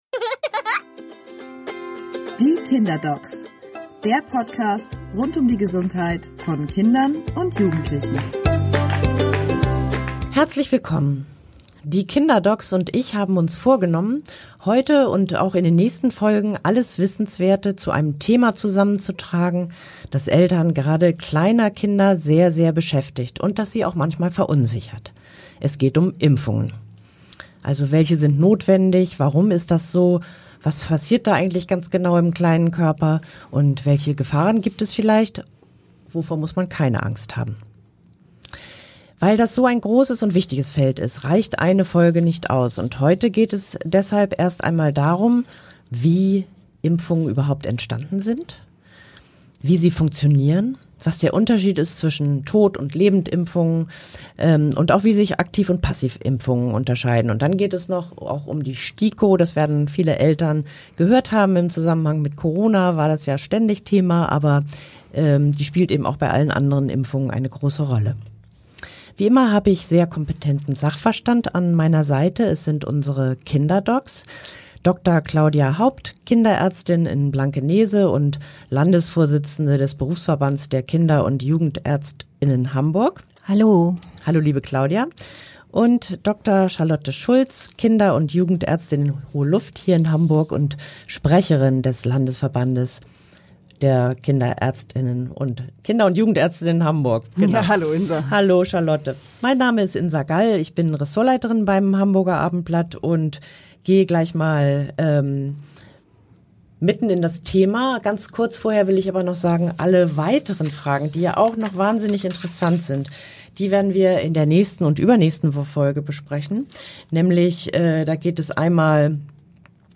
Wie die Impfung im Einzelnen funktioniert, was der Unterschied zwischen passiven und was aktiven Impfungen ist und warum neben dem Schutz des Einzelnen auch der Gemeinschaftsschutz so wichtig ist, erklären die beiden Kinderärztinnen im Podcast „Die KinderDocs“.